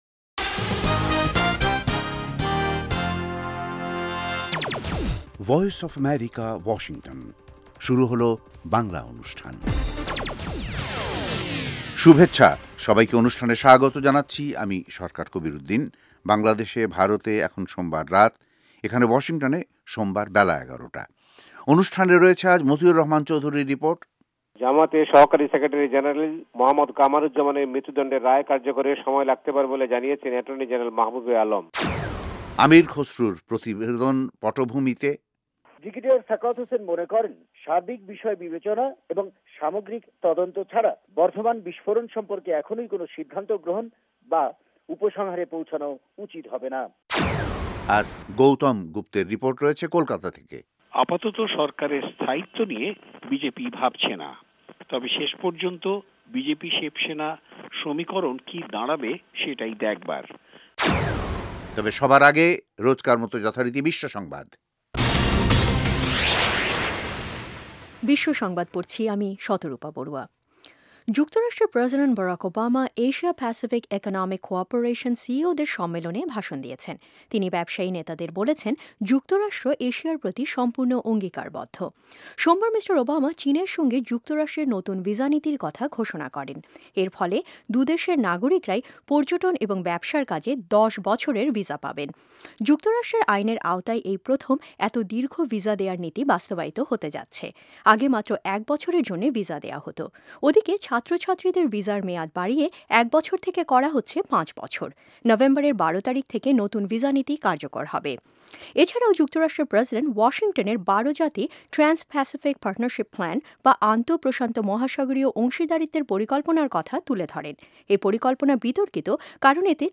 News 1600 (Daily Program)